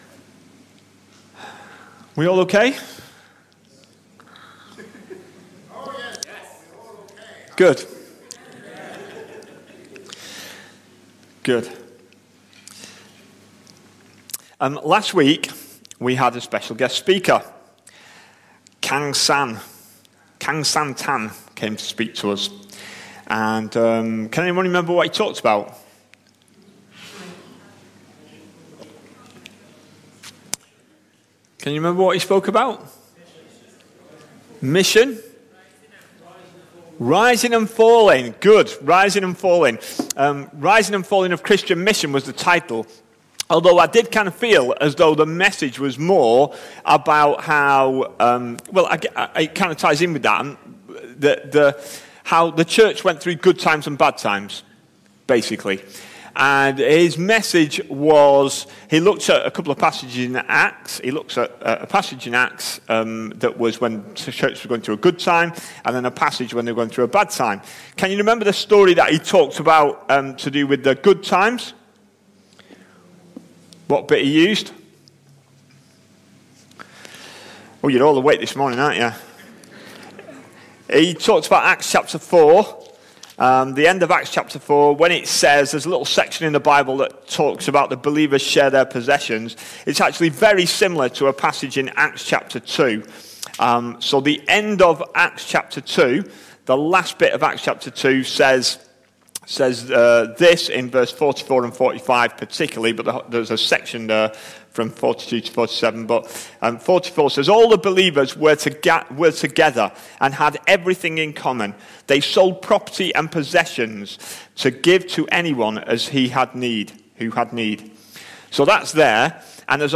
A sermon preached on 15th May, 2022.